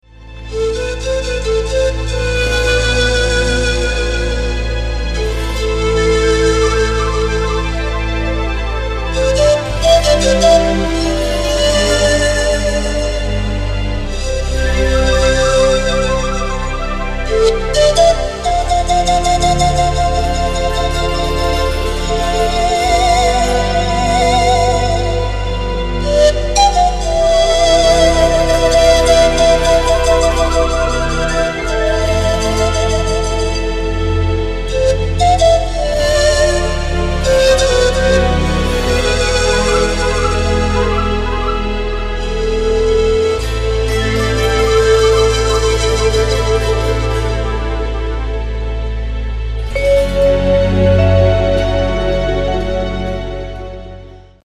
• Качество: 211, Stereo
спокойные
без слов
инструментальные
нежные
Флейта Пана
панфлейта